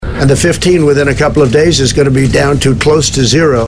FEB. 26: Donald Trumps states that only fifteen people have the virus,